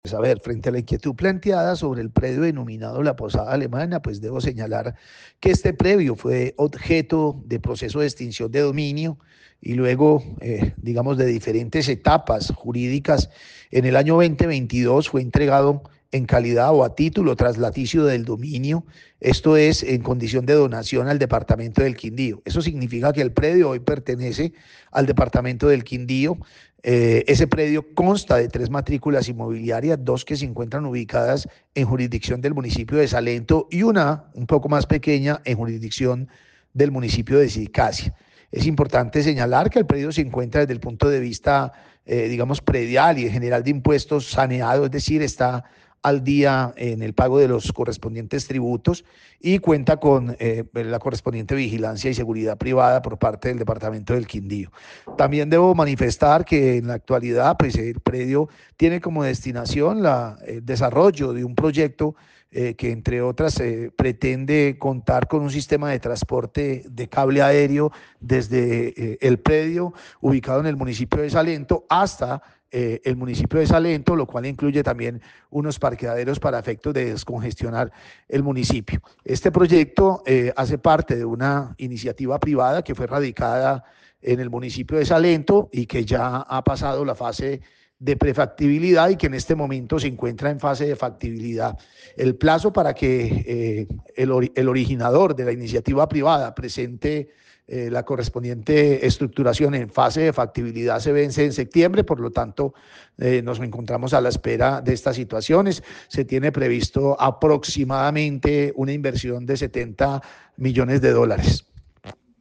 Juan Carlos Alfaro, secretario jurídico de gobernación del Quindío
En Caracol Radio Armenia hablamos con Juan Carlos Alfaro, secretario en representación judicial de la gobernación del Quindío que entregó detalles de cómo está el predio hoy y las proyecciones o proyecto que se tiene pensando desarrollar en este predio.